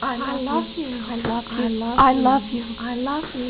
iloveu_low.au